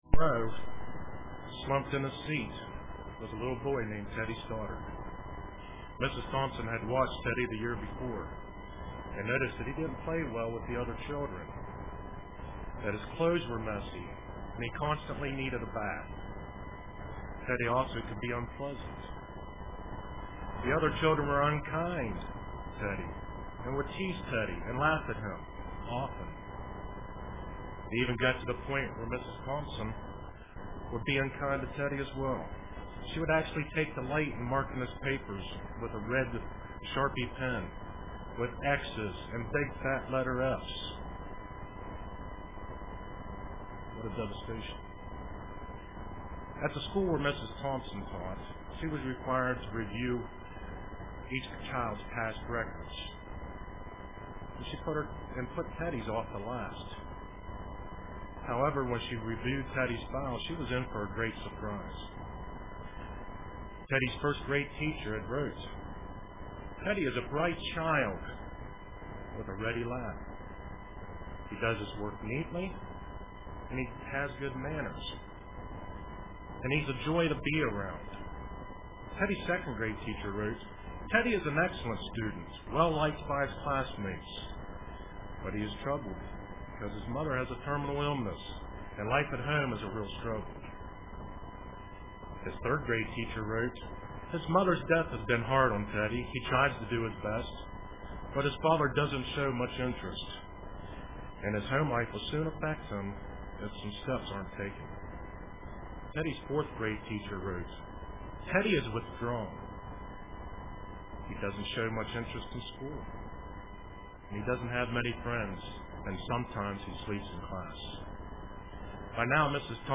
Print The Law of Kindness UCG Sermon Studying the bible?